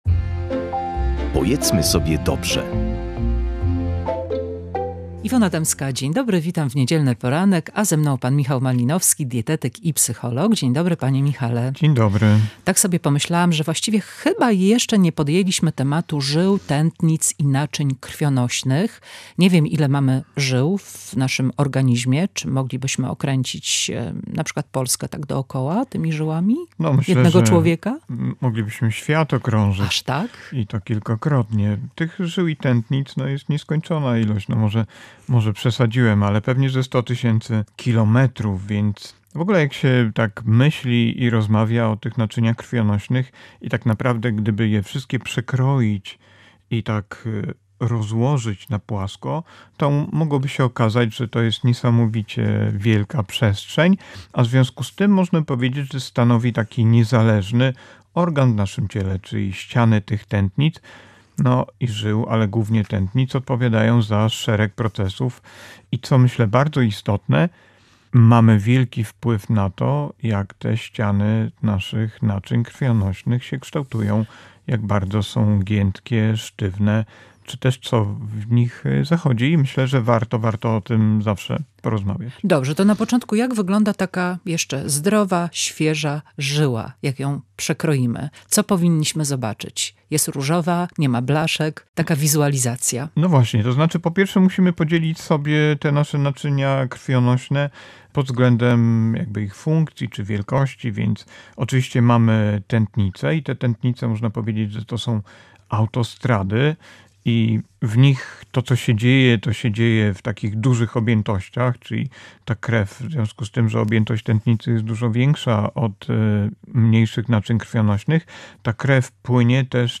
Wyjaśnia dietetyk